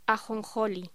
Locución: Ajonjoli